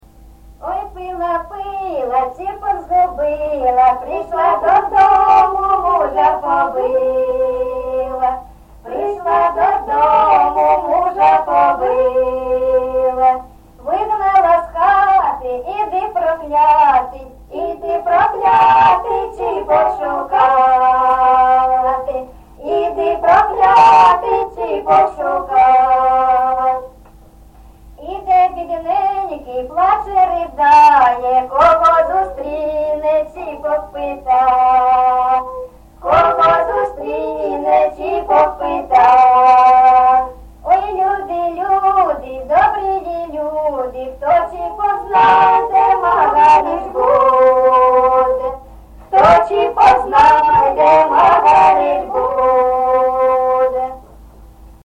ЖанрПісні з особистого та родинного життя, Пʼяницькі
Місце записус-ще Троїцьке, Сватівський район, Луганська обл., Україна, Слобожанщина